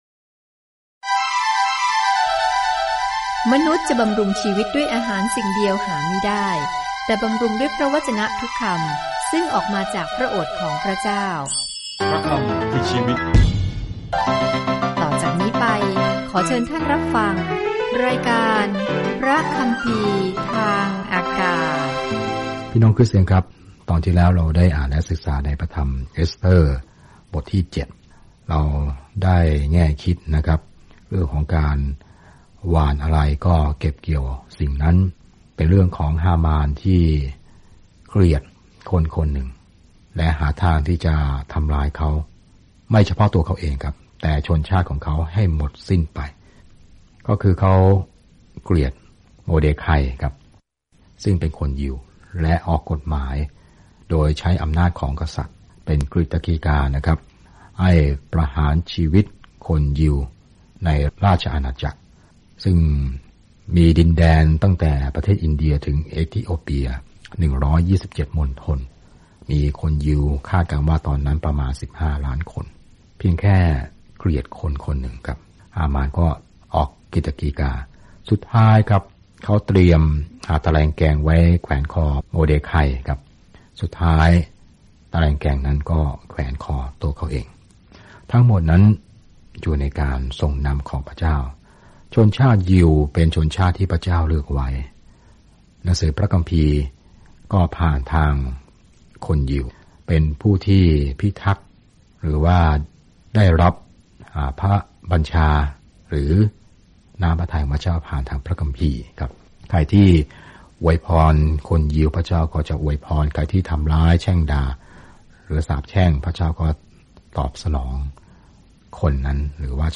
เดินทางผ่านเอสเธอร์ทุกวันในขณะที่คุณฟังการศึกษาด้วยเสียงและอ่านข้อที่เลือกจากพระวจนะของพระเจ้า